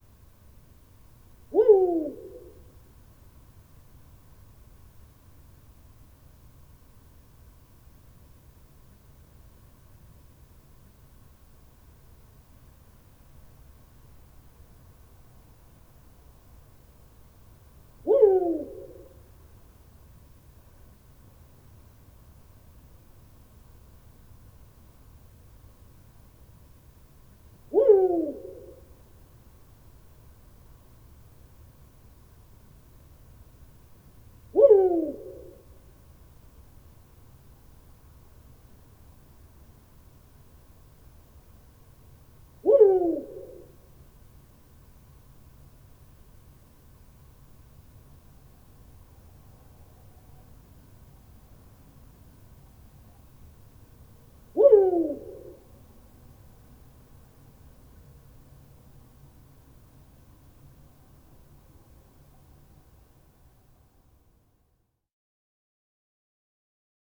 Pharaoh Eagle-owl
Pharaoh Eagle-Owl has a very distinctive hoot consisting of a single, rapidly descending and diminishing note.
19-Pharaoh-Eagle-Owl-Hooting-Of-Male.wav